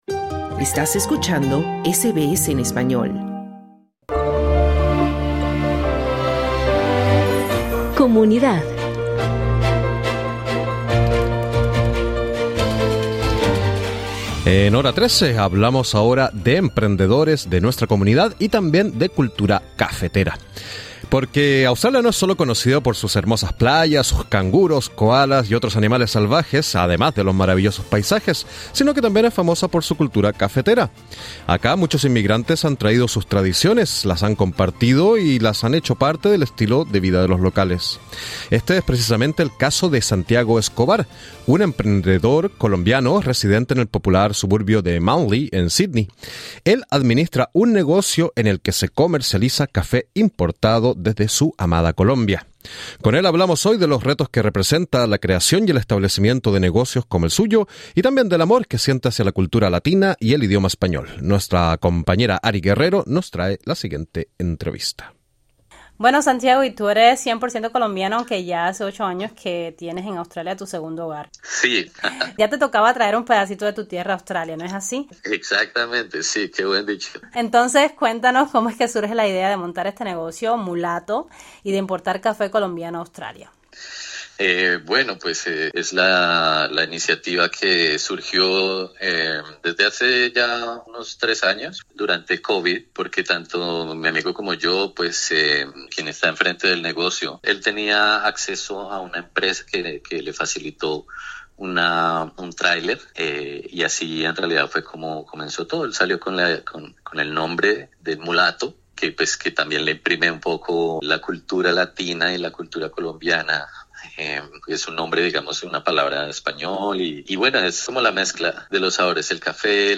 SBS Spanish habló con él acerca de los retos que representa la creación y el establecimiento de negocios como el suyo y acerca del amor que él siente hacia su cultura latina y hacia su idioma español.